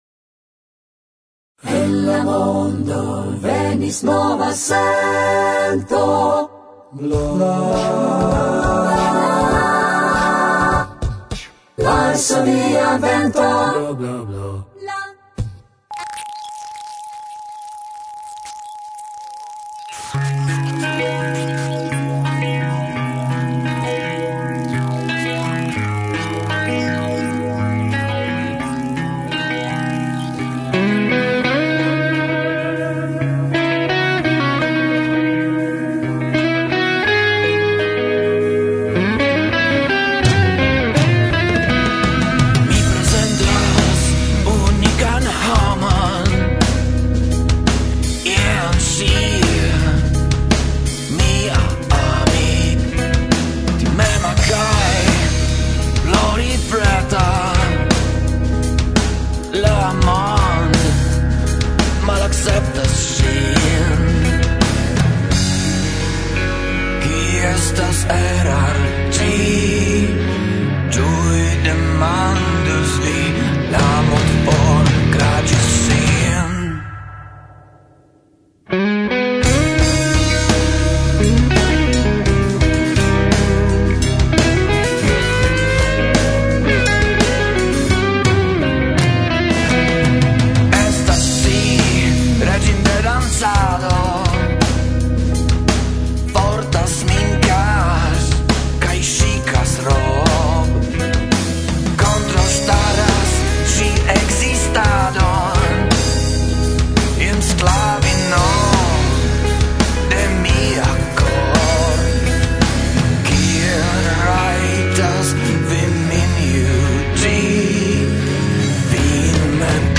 Mikrofona renkontiĝo
En la provizora studio enkonduke